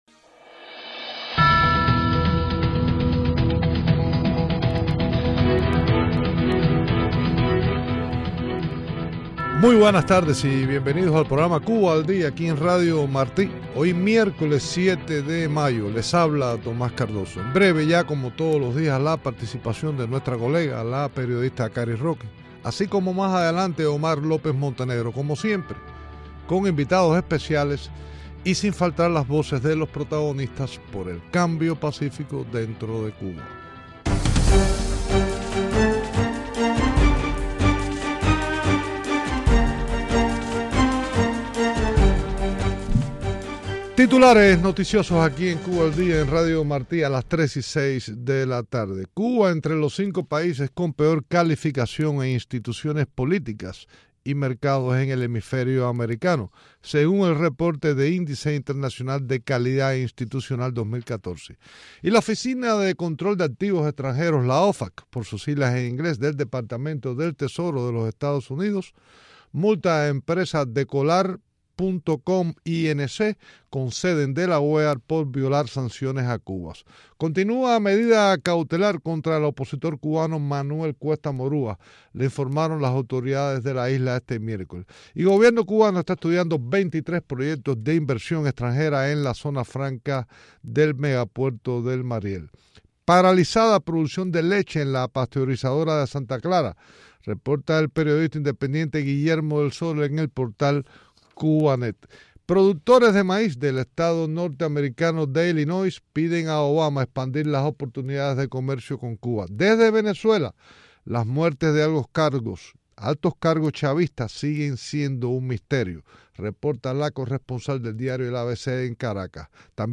Entrivistas